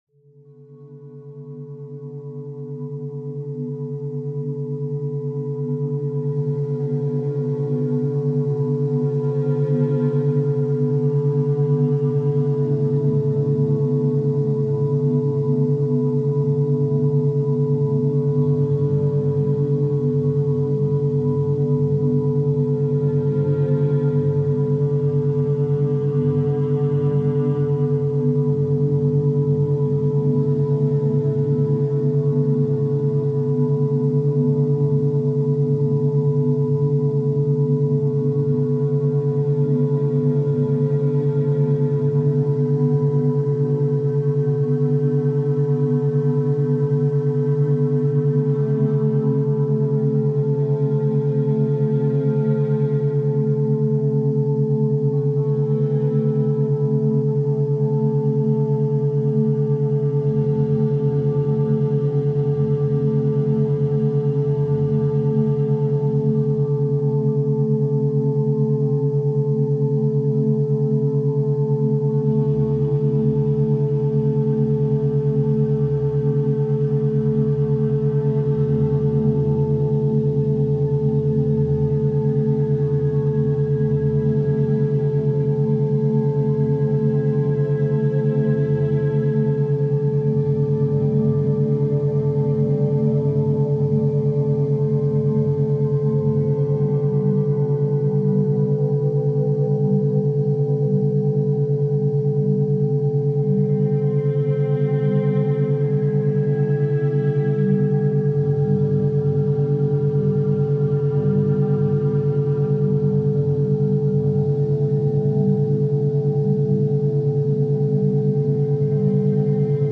432 Hz Meditation – Universal Energy Connector
Study Sounds, Background Sounds, Programming Soundscapes, Coding Beats 432 Hz Meditation – Universal Energy Connector May 11 2025 | 01:30:00 1x 00:00 / 01:30:00 Subscribe Share RSS Feed Share Link Embed